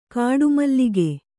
♪ kāḍu mallige